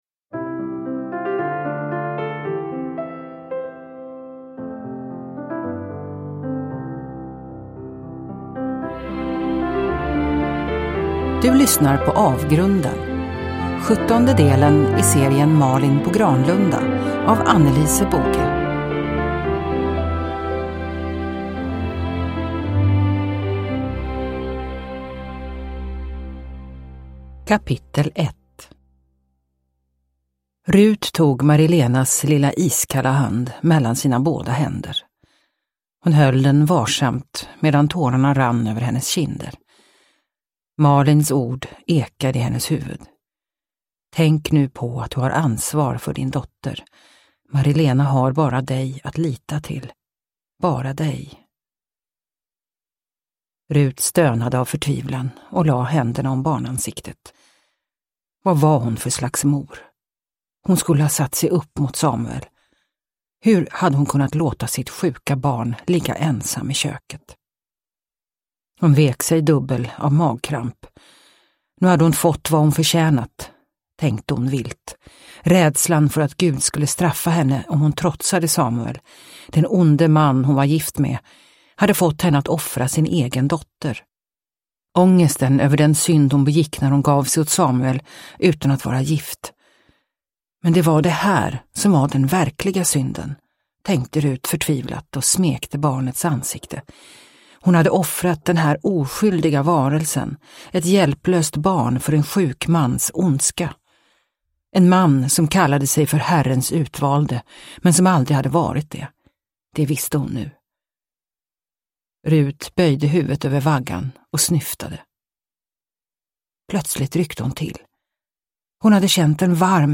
Avgrunden – Ljudbok – Laddas ner